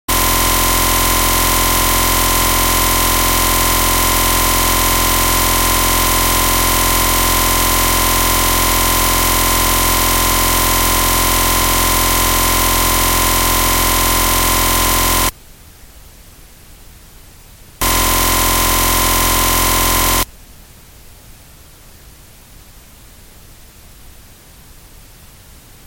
Enlace al archivo de audio amplificado , tenga en cuenta el ruido blanco y silencioso cuando pcm dac se establece en 0.
El ruido suena periódico. Suena un poco como el ruido que se puede encontrar en un riel de alimentación en una placa digital. Parece que algo "sucede" alrededor de 10 Hz, podría ser una CPU que se despierta, consume energía y agrega picos a los voltajes del riel.
AmplifiedNoise.mp3